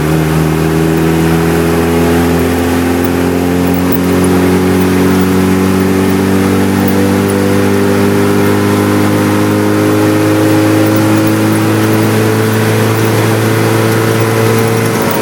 Index of /server/sound/vehicles/lwcars/uaz_452
fourth_cruise.wav